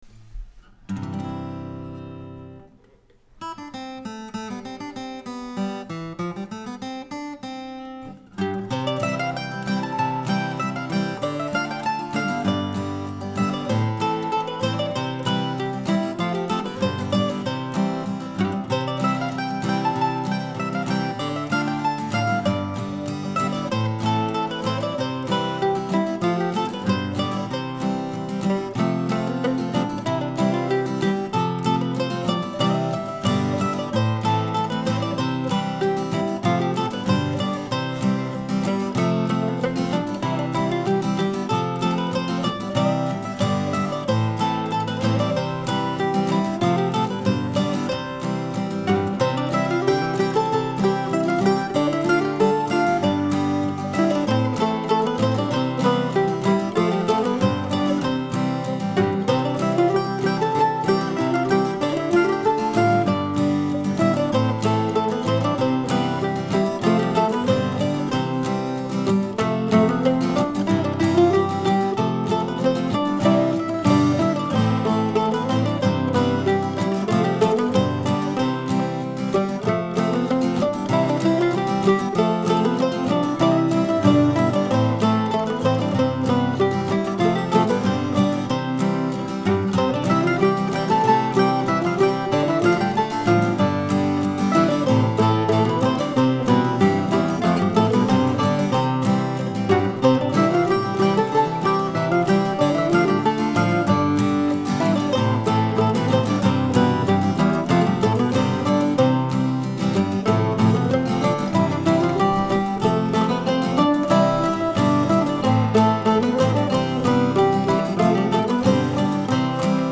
I didn't create any harmony parts for this one but I did record it in multiple octaves using my recently acquired Peter Coombe Classical Flattop mandolin, my main old Gibson mandolin, and a nice, recent Taylor guitar.